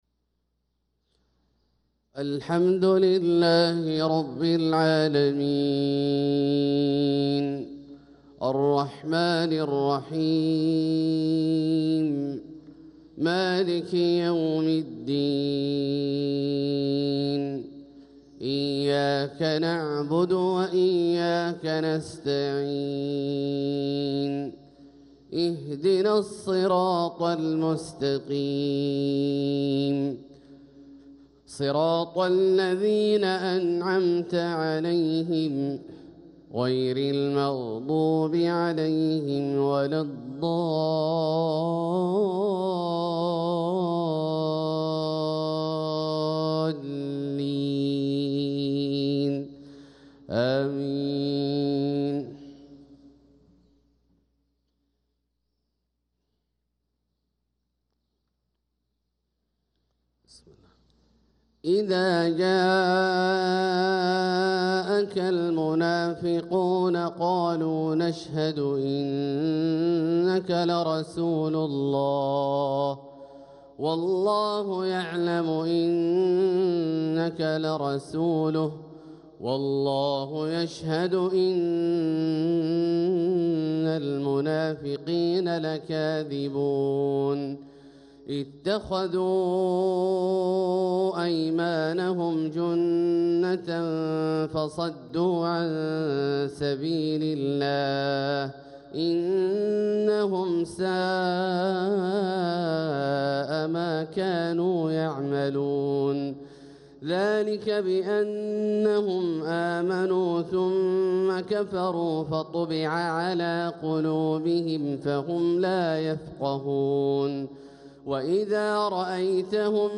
صلاة الفجر للقارئ عبدالله الجهني 25 ربيع الأول 1446 هـ
تِلَاوَات الْحَرَمَيْن .